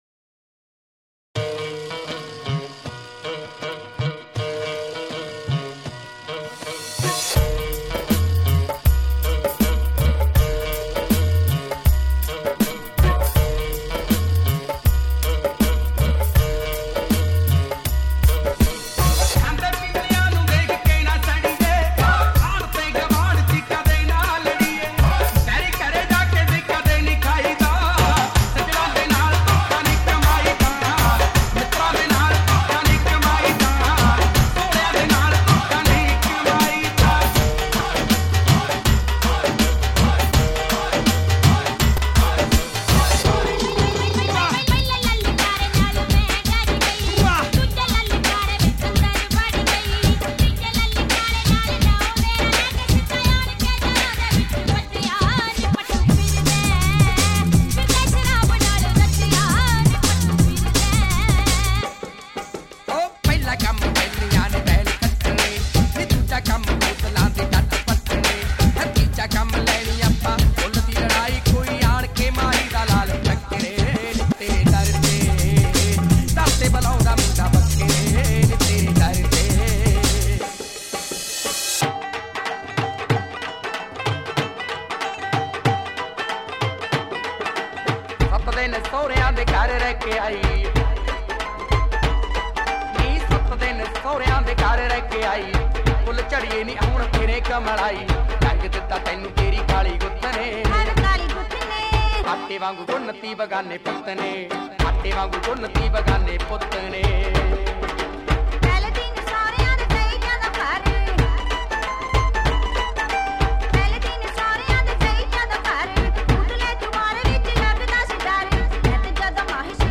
Category: Punjabi